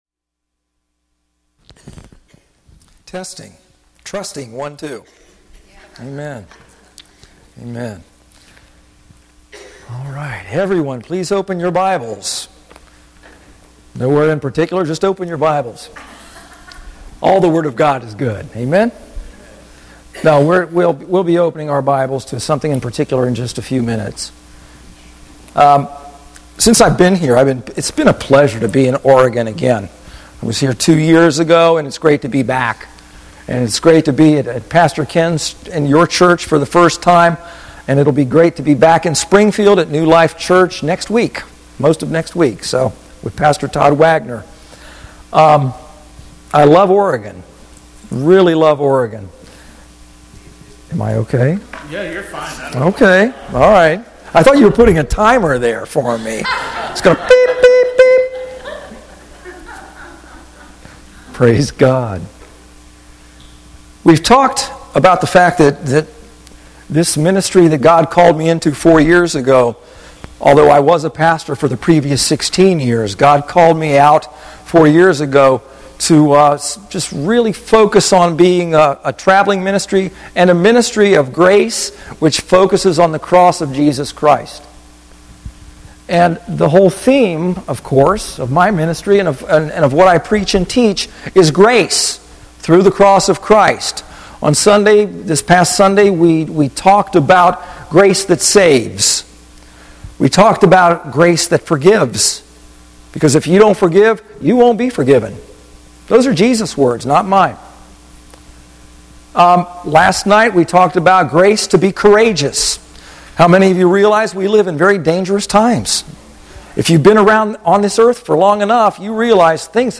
Audio Sermons | Your Place of Grace Ministries